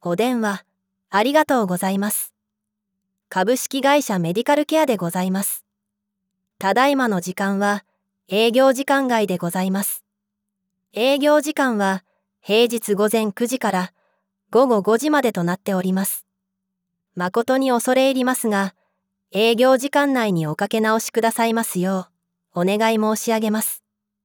0-女性2-HO_AutoRes_221227_3